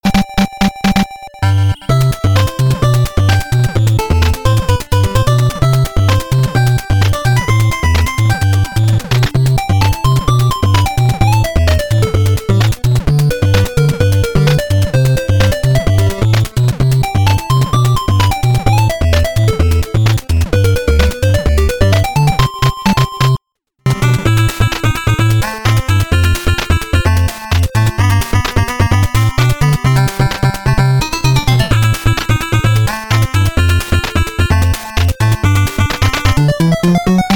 I’ve attached the song and what I’ve done ‘successfully’ to this point (noise reduction on :23-24).
That’s all the places where the tune hit distortion from being too loud.